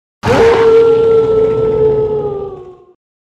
Здесь вы найдете реалистичные аудиозаписи воя, рычания и других эффектов, связанных с этими легендарными существами.
Звук воя оборотня в ночи